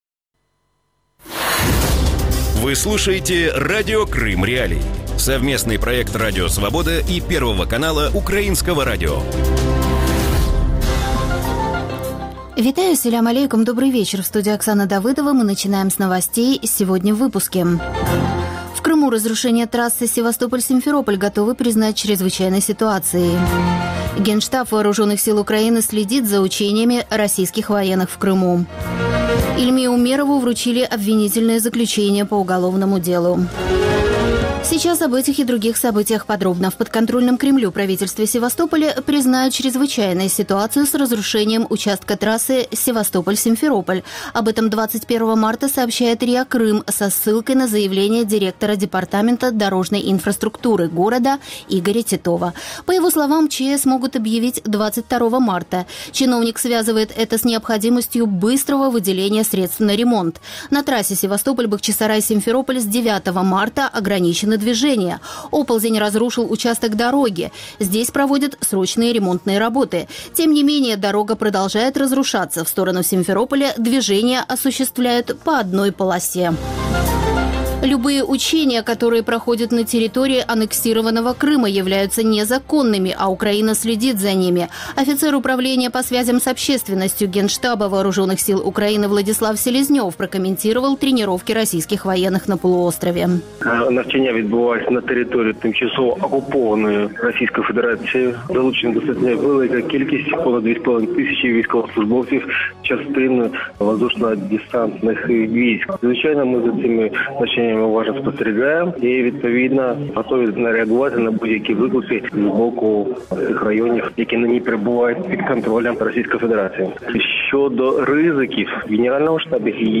Вечерний выпуск новостей о событиях в Крыму. Все самое важное, что случилось к этому часу на полуострове.